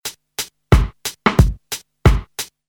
reggae drumloops soundbank 2